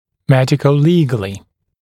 [ˌmedɪkə(u)’liːgəlɪ][ˌмэдико(у)’ли:гэли]с учетом медицинских и правовых аспектов